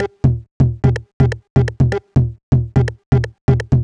cch_jack_percussion_loop_service_125.wav